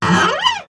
Free Samples : samples de effects .Efectos especiales,sonidos extrańos..